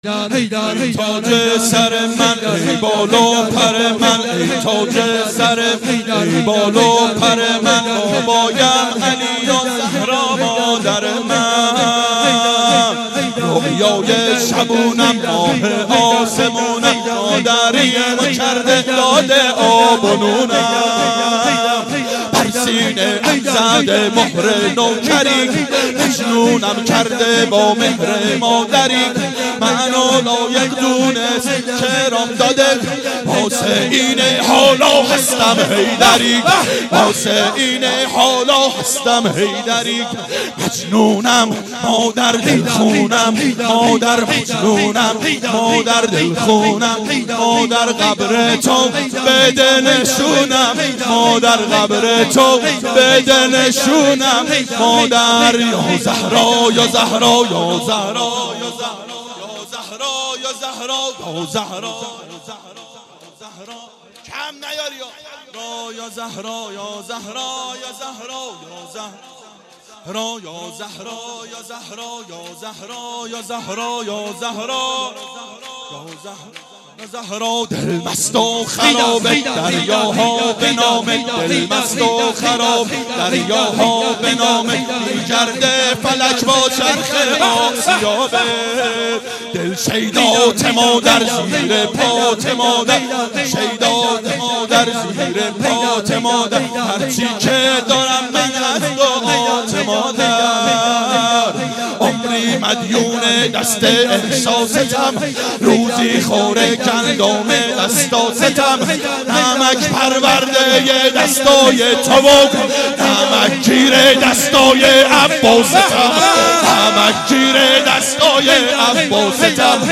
• فاطمیه